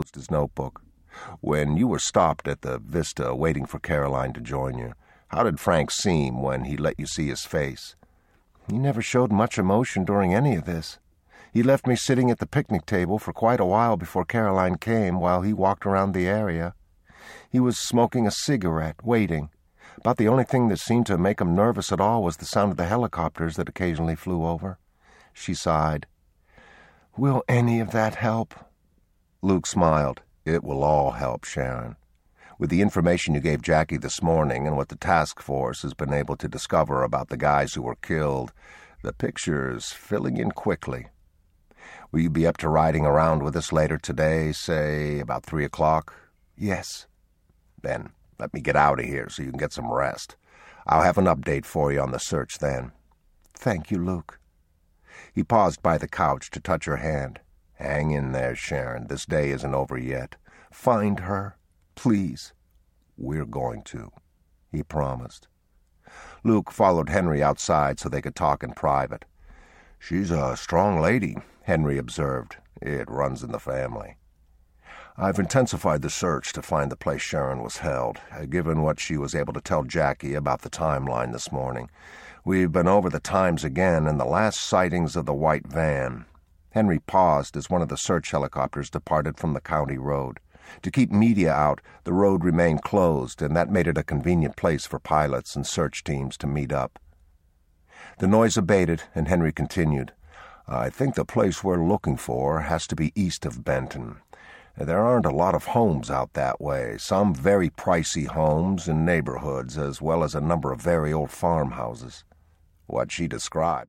True Courage Audiobook
9.25 Hrs. – Unabridged